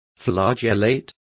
Complete with pronunciation of the translation of flagellate.